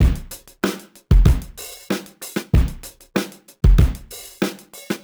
Index of /musicradar/sampled-funk-soul-samples/95bpm/Beats
SSF_DrumsProc2_95-01.wav